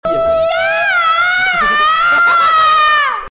Play, download and share Inoxtag yahaaaa original sound button!!!!
inoxtag-yahaaaa.mp3